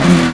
rr3-assets/files/.depot/audio/sfx/gearshifts/f1/renault_downshift_1.wav
renault_downshift_1.wav